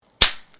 bubblewrap (1).mp3